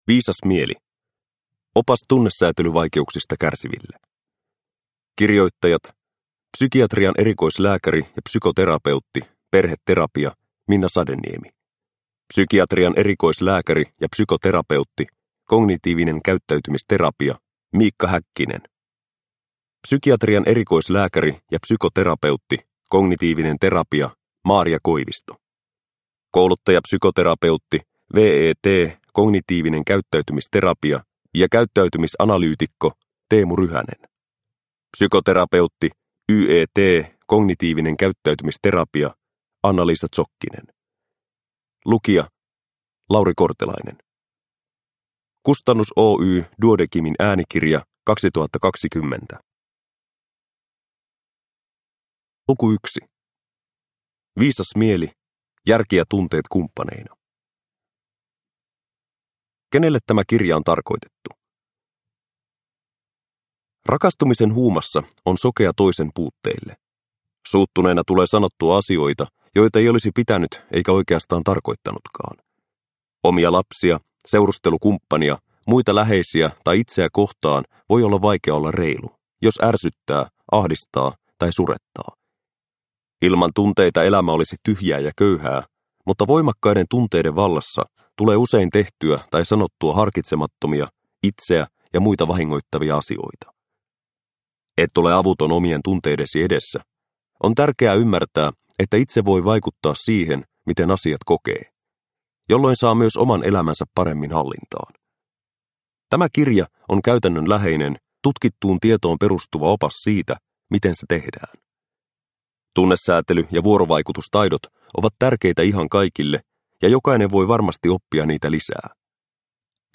Viisas mieli – Ljudbok – Laddas ner